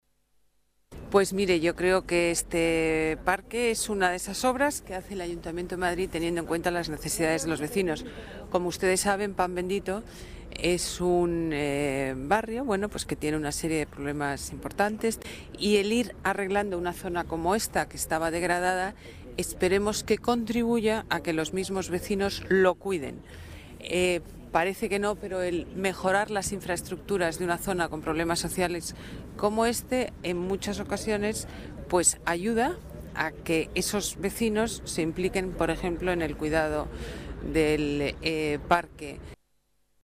Nueva ventana:Declaraciones de la teniente de alcalde y delegada de Medio Ambiente, Ana Botella